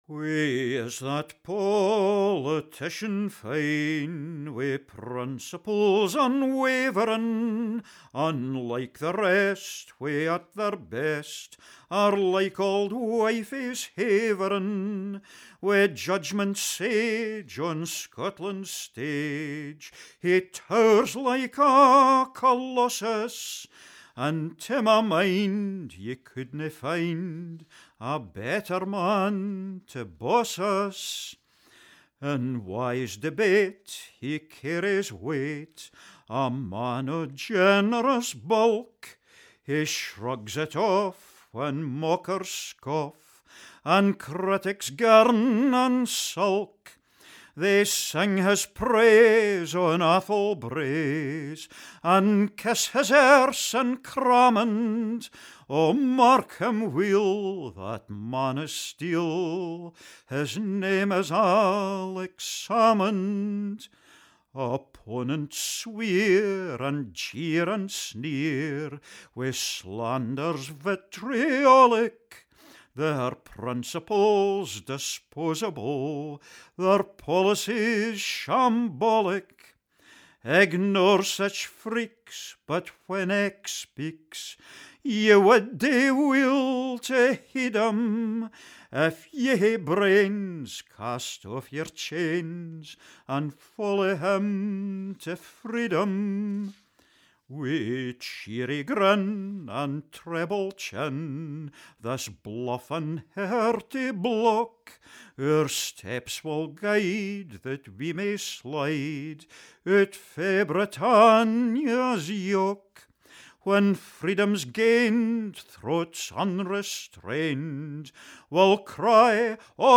modern Scottish folk song